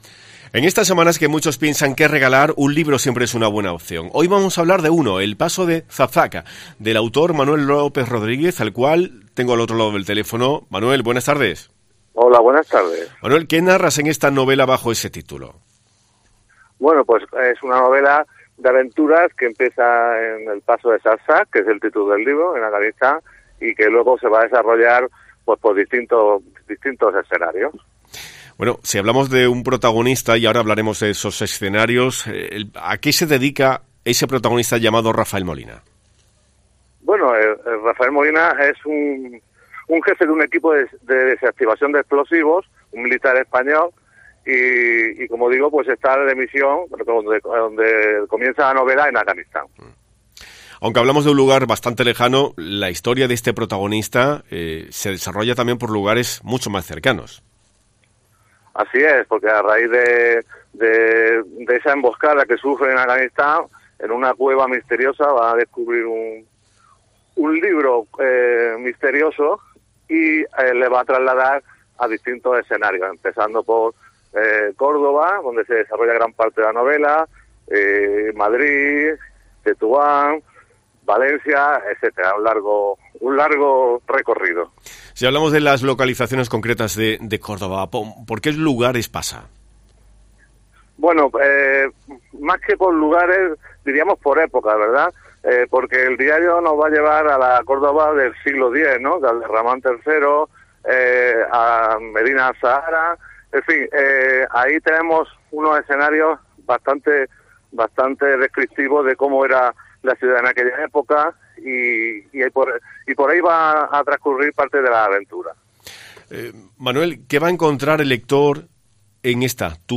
Nos lo ha contadon durante la entrevista mantenida en Mediodía COPE.